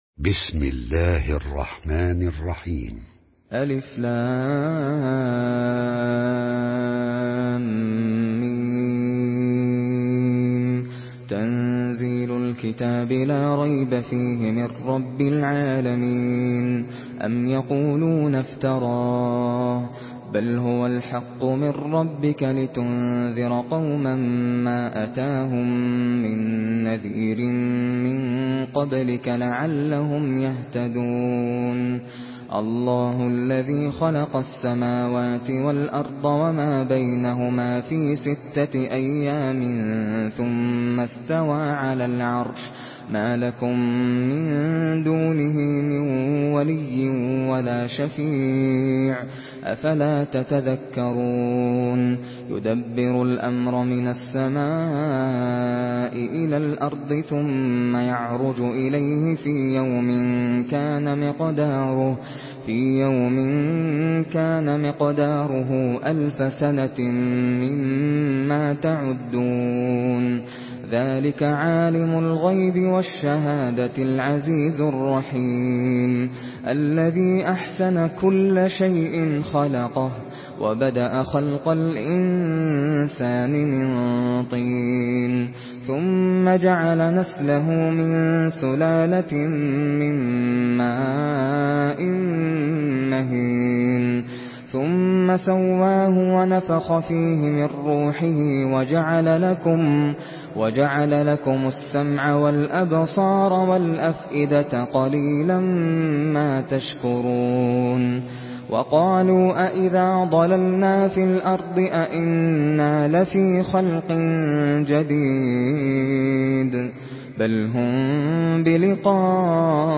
Surah Secde MP3 by Nasser Al Qatami in Hafs An Asim narration.
Murattal Hafs An Asim